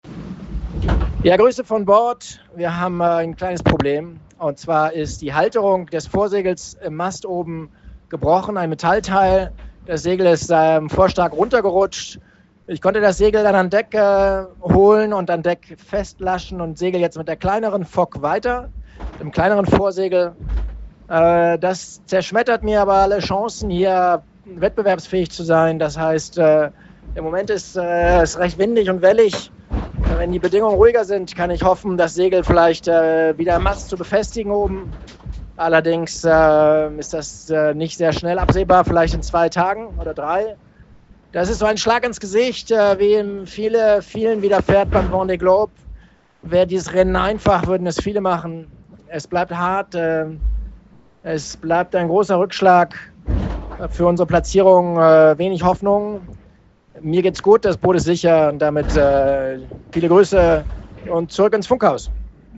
Er äußert sich in einer Audio-Aufnahme.